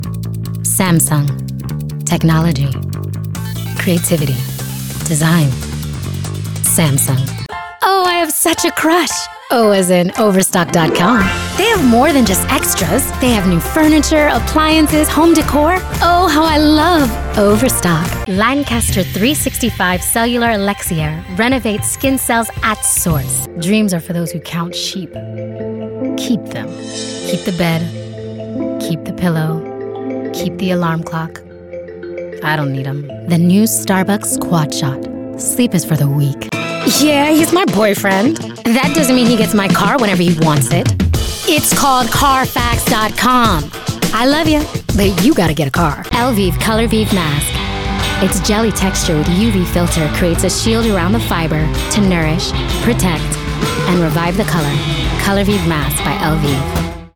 Commercial Demo Reel
Voice Type:  Expressive, rich, conversational, with a touch of rasp.